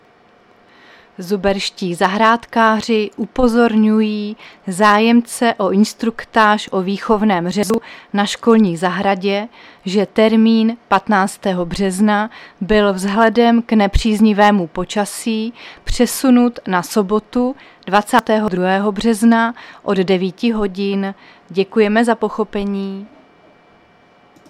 Záznam hlášení místního rozhlasu 13.3.2025
Zařazení: Rozhlas